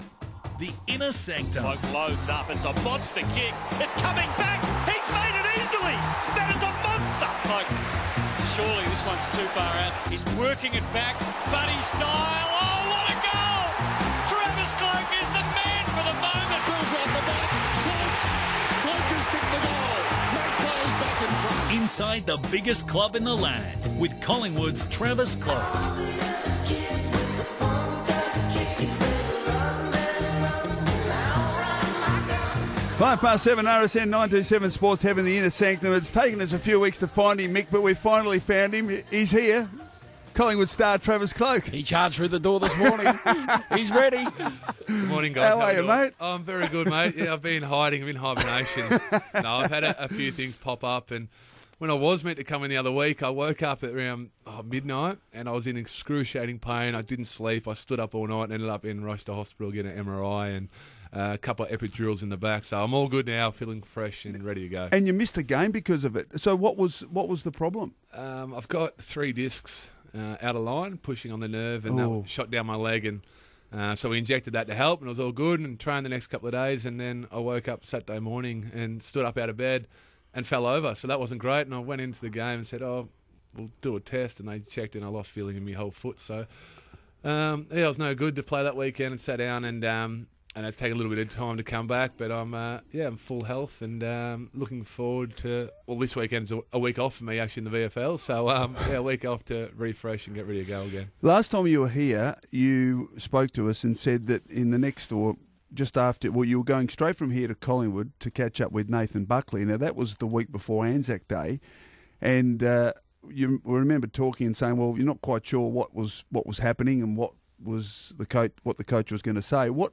Radio: Travis Cloke on RSN